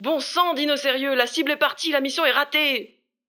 VO_ALL_EVENT_Temps ecoule_02.ogg